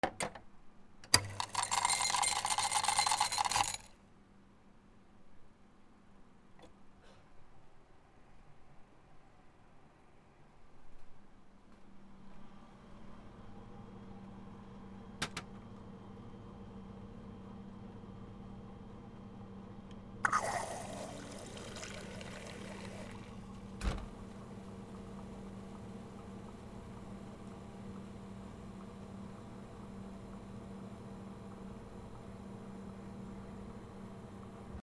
ドリンクバー 氷を入れる～炭酸飲料をコップに入れる